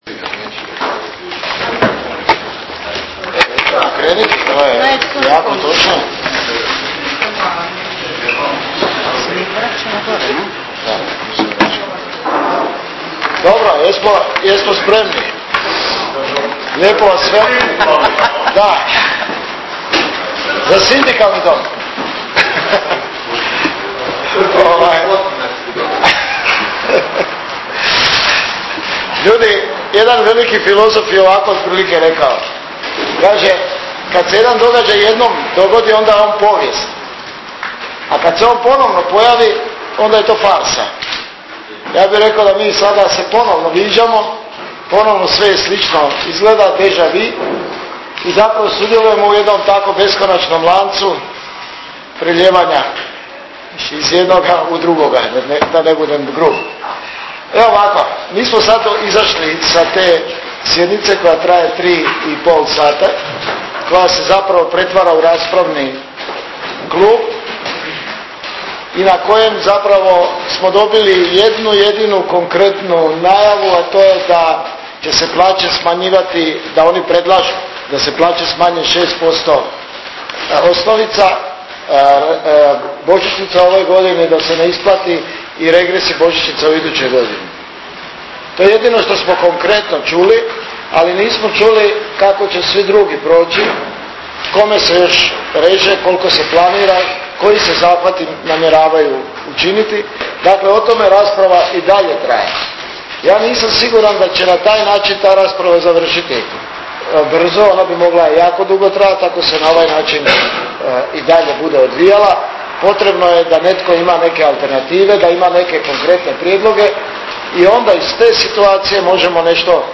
Danas je Matica hrvatskih sindikata održala tiskovnu konferenciju na temu: Rebalans proračuna i plaće, koju možete poslušati u nastavku.